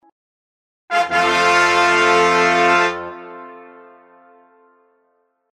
Звуки тадам
Тадам на трубе громкий